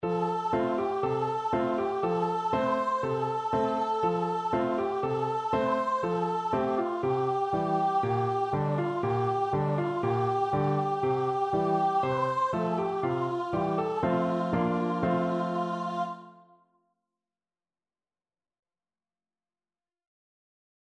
Voice
4/4 (View more 4/4 Music)
Allegro (View more music marked Allegro)
F major (Sounding Pitch) (View more F major Music for Voice )
Traditional (View more Traditional Voice Music)